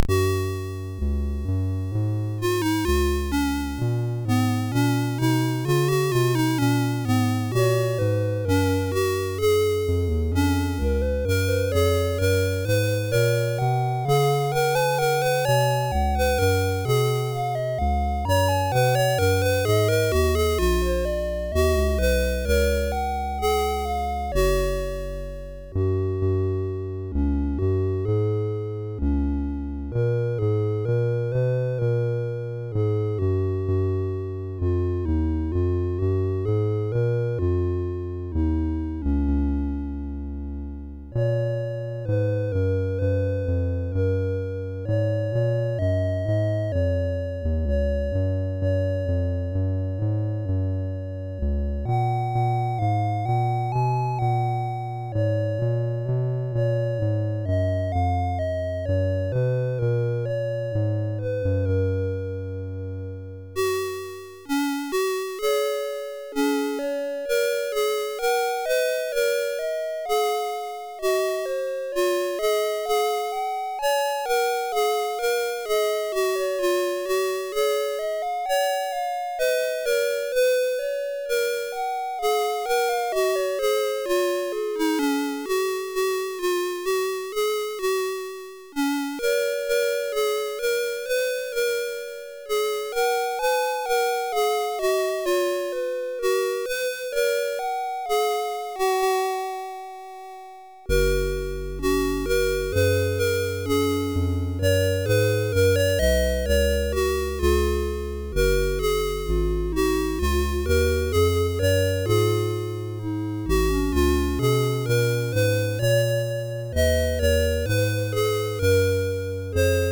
Commodore SID Music File